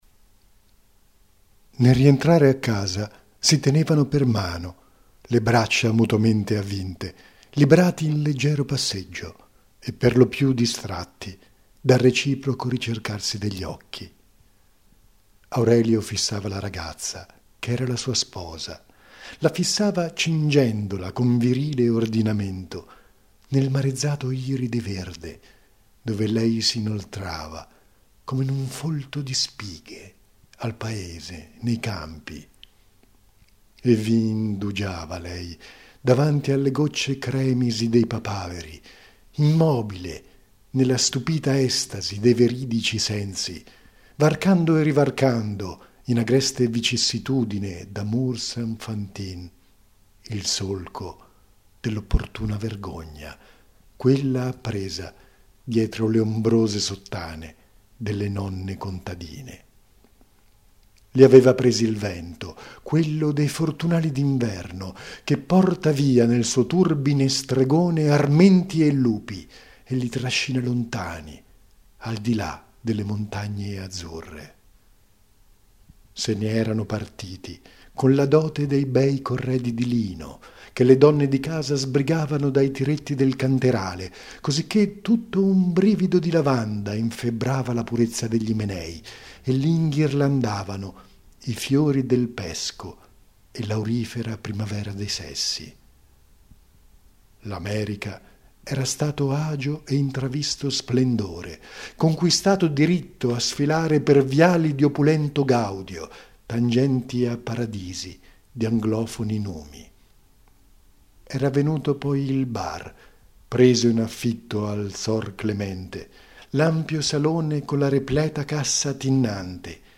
Recitazione
che ho fatto mentre provavo da solo a casa (non sono "dal vivo").